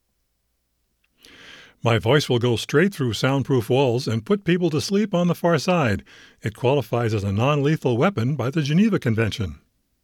I don’t have a Yeti, but this is a normal volume test clip I shot.